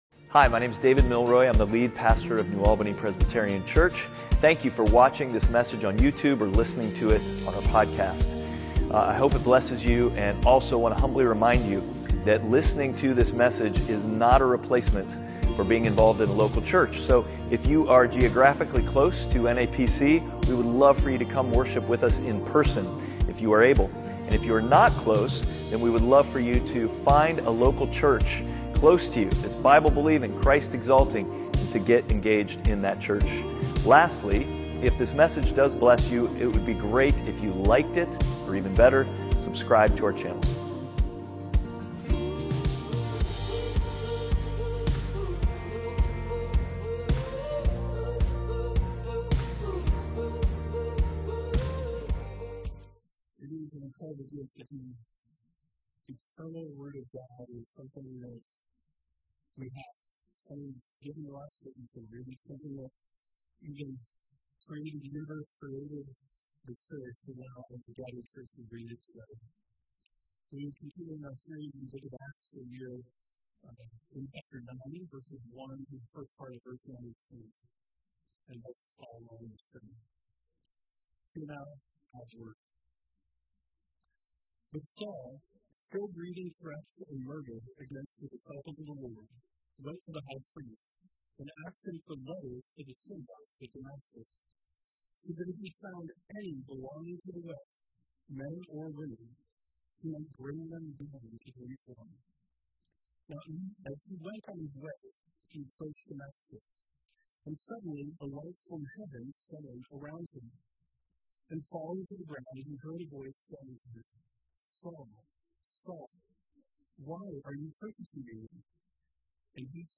Outward Passage: Acts 9:1-19a Service Type: Sunday Worship « Outward